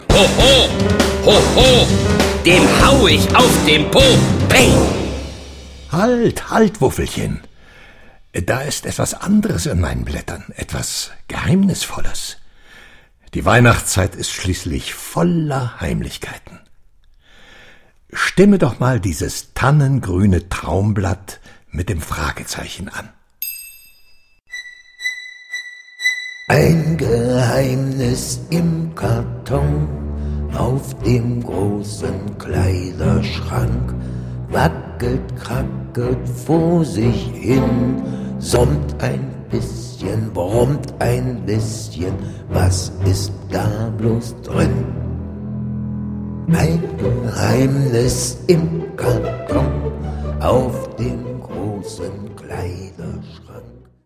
Ravensburger Der Traumzauberbaum 4 - Herr Kellerstaub rettet Weihnachten ✔ tiptoi® Hörbuch ab 3 Jahren ✔ Jetzt online herunterladen!